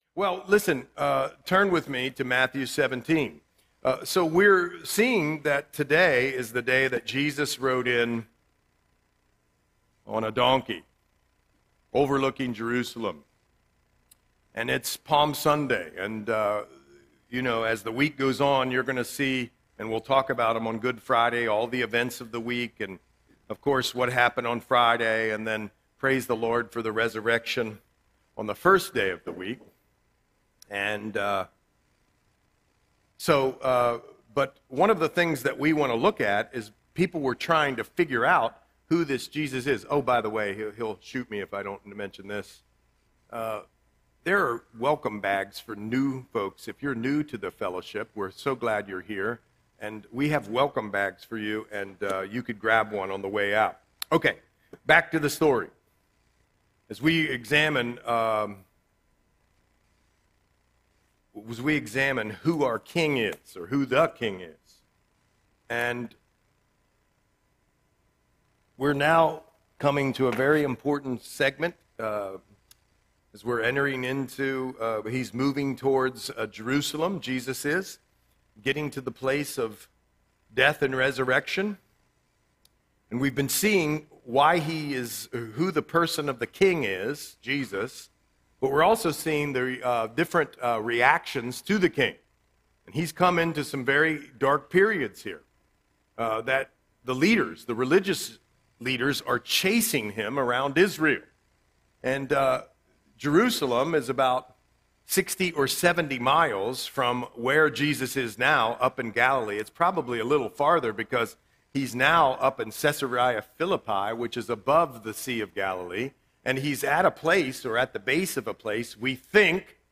Audio Sermon - March 29, 2026